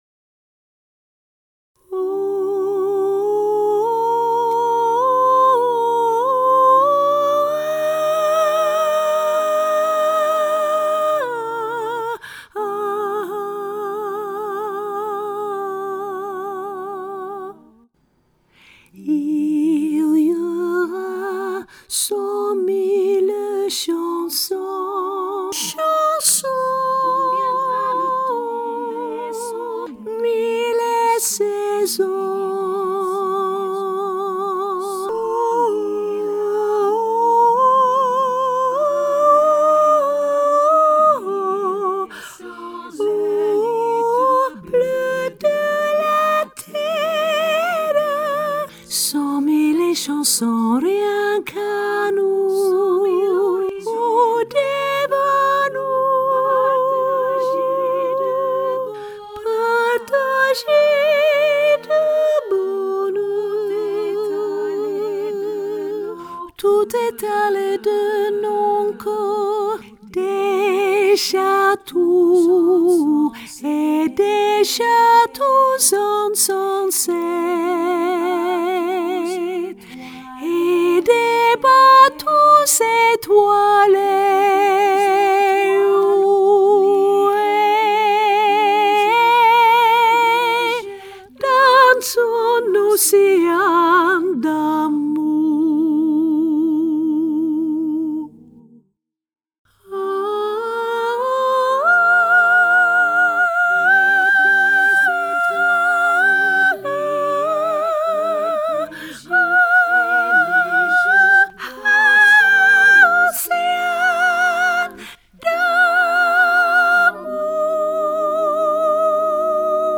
hoog sopraan